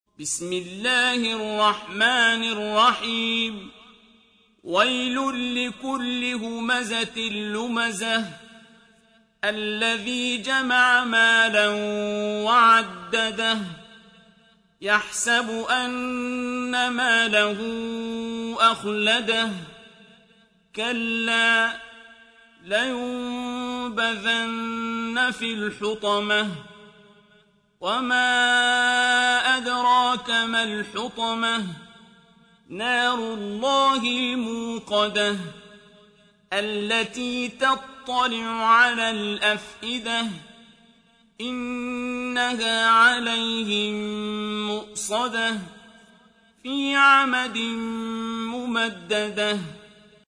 سورة الهمزة | القارئ عبدالباسط عبدالصمد